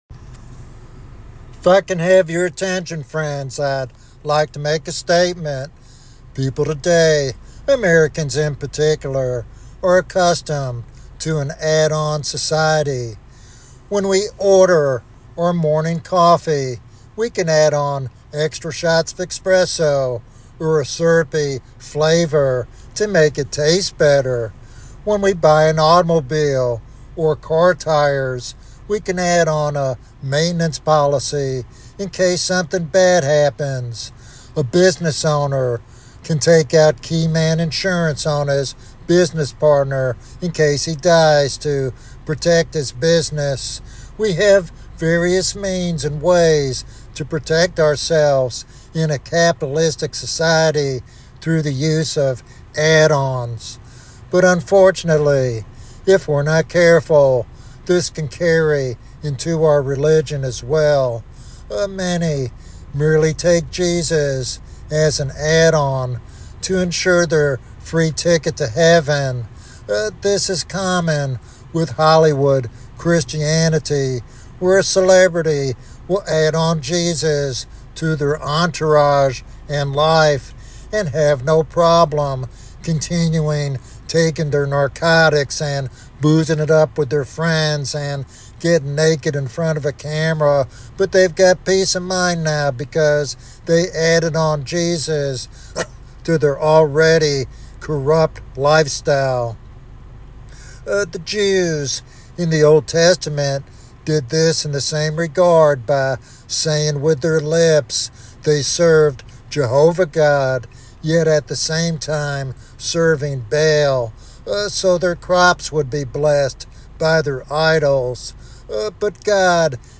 This sermon challenges believers to examine their faith deeply and flee to the saving grace of Jesus.